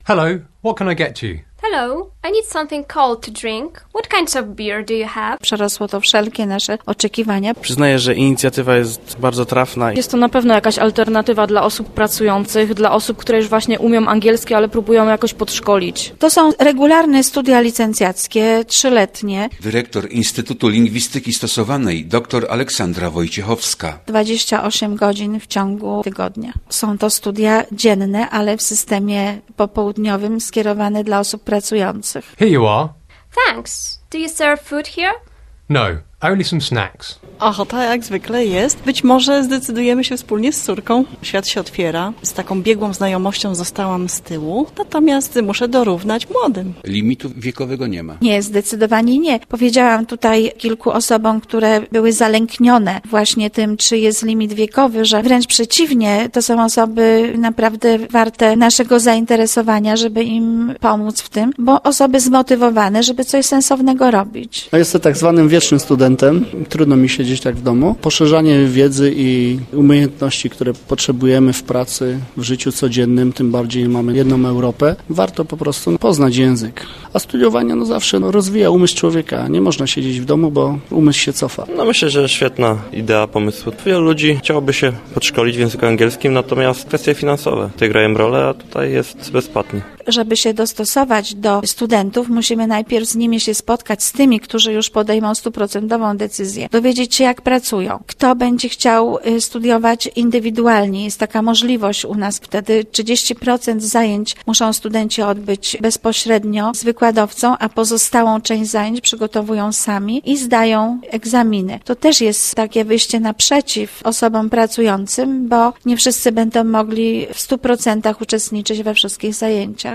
Z zainteresowanymi z obu stron rozmawiał